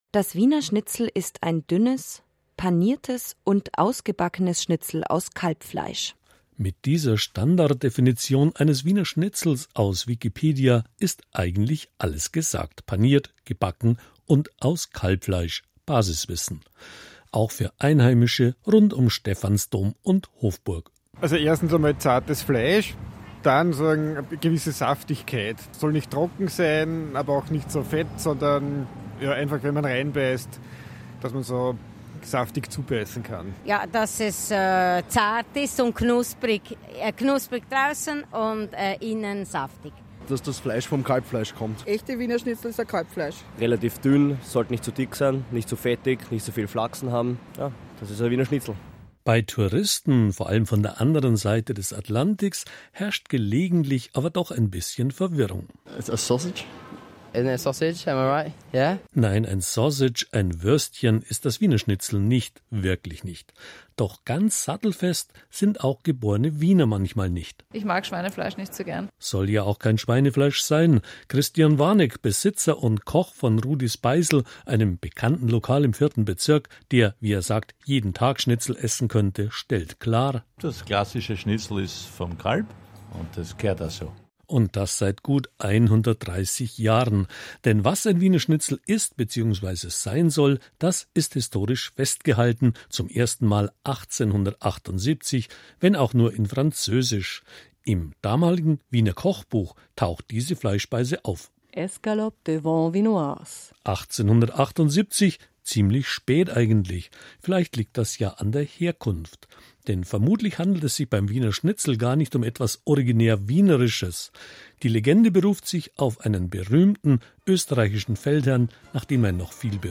Jedenfalls ist dieser angebliche Schnitzeltag Grund genug, um nachzufragen, was ein „echtes“ Wiener Schnitzel eigentlich ausmacht. Beantwortet wird diese Frage historisch mit Zitaten und gegenwärtig von Wienern, Touristen und einem Chefkoch, der nach eigener Aussage jeden Tag Wiener Schnitzel essen könnte.